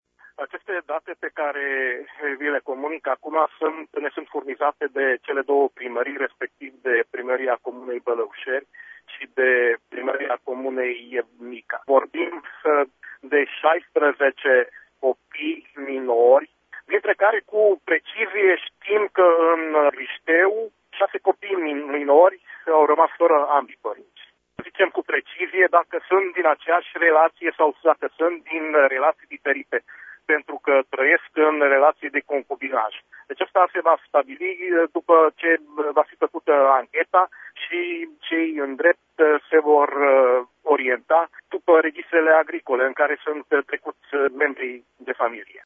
Situația va fi clarificată zilele următoare, a declarat Nagy Zsigmond:
subprefect-mures.mp3